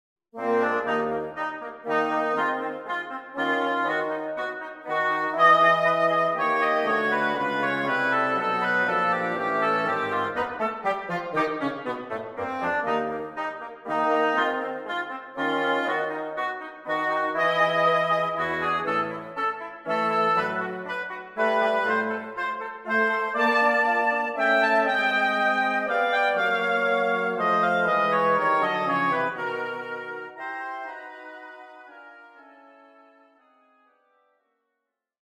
Flexible Woodwind Ensemble
Minuet excerpt (2 Oboes and 2 Bassoons)